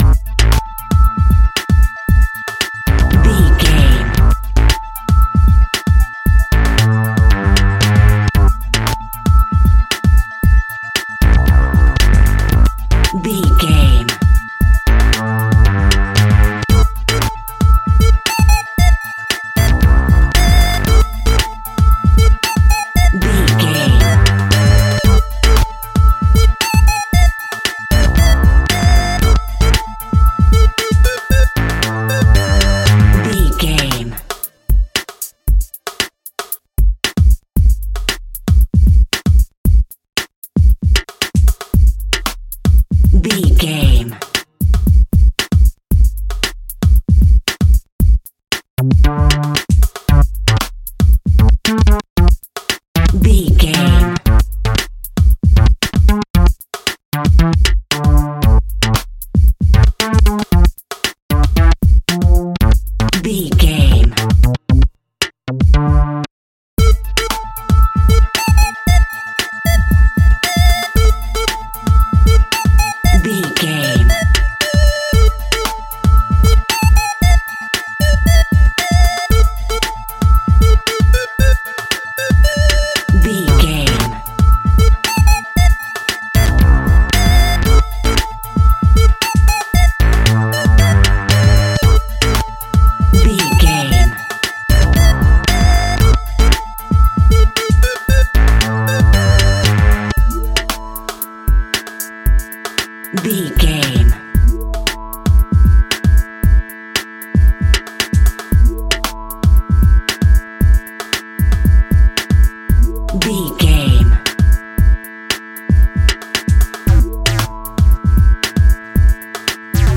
Aeolian/Minor
Fast
hip hop
hip hop instrumentals
funky
groovy
east coast hip hop
electronic drums
synth lead
synth bass